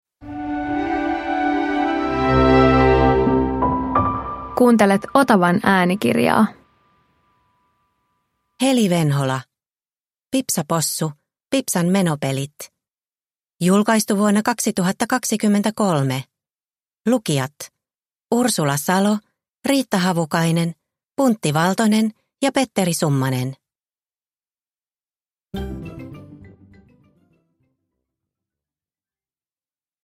Pipsa Possu - Pipsan menopelit – Ljudbok – Laddas ner